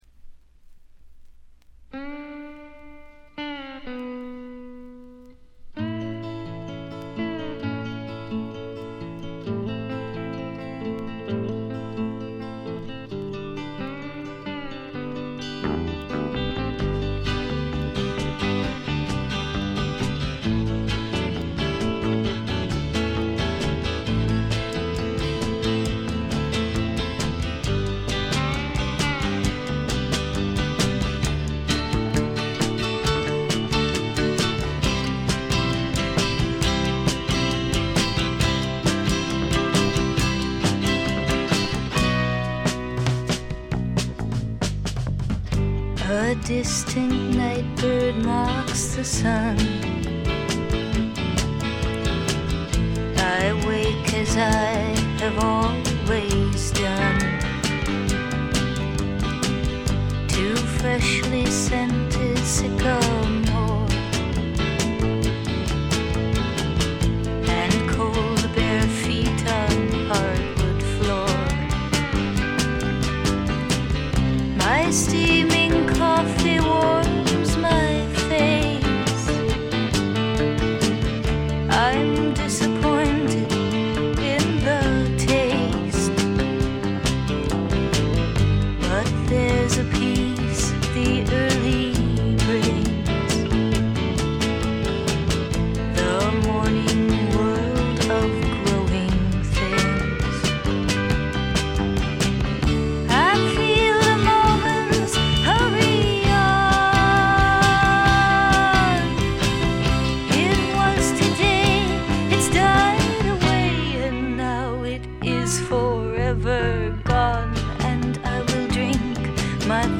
ほとんどノイズ感無し。
ルックスよりも少しロリ寄りの声で、ちょっとけだるくてダークでたまらない魅力をかもしだしています。
試聴曲は現品からの取り込み音源です。
Recorded at T.T.G. Studios, Hollywood